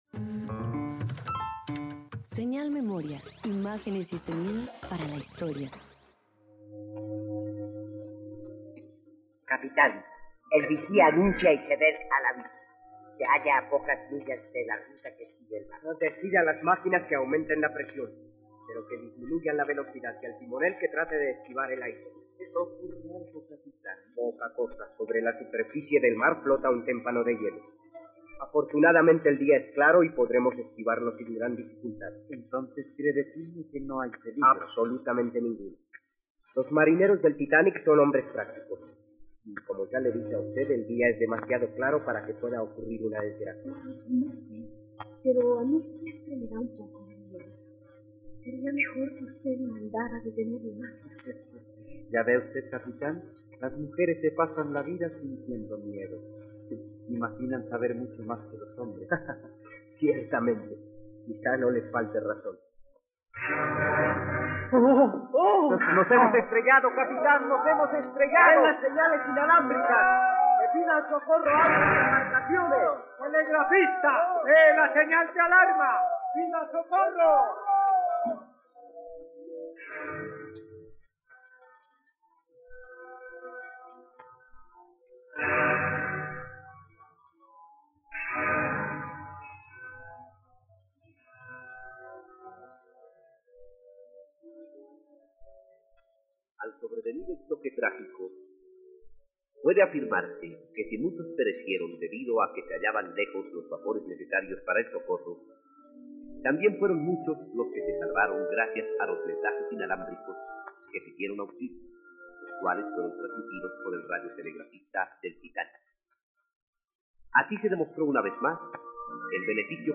El naufragio del Titanic dramatizado por la Radio Nacional